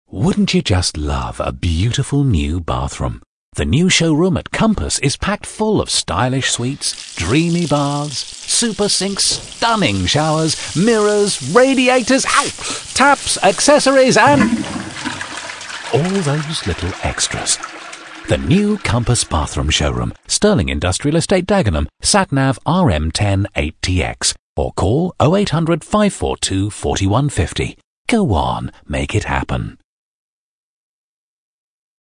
This 30-second commercial was created to run together with an extensive campaign of local press and outdoor over 26 weeks to announce the opening of their new Bathroom Showroom at their huge 2-acre location in Dagenham, Essex.